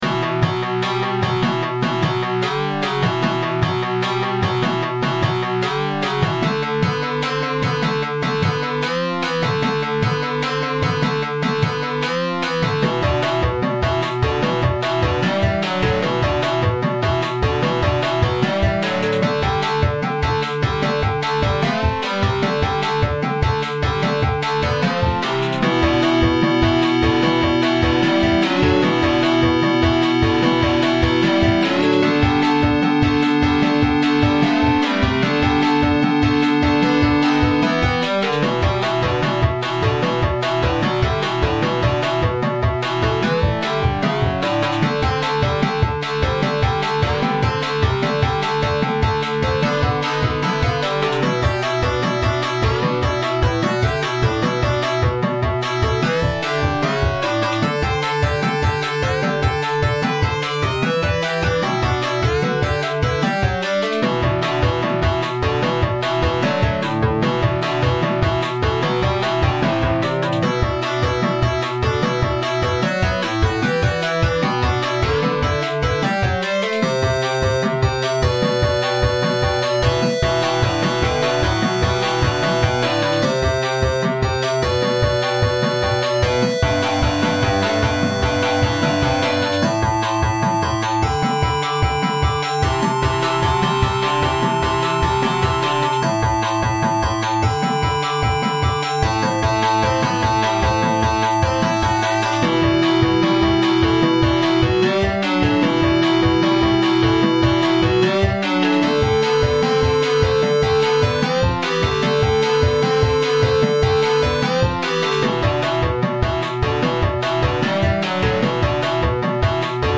Will you choose this awful background music ...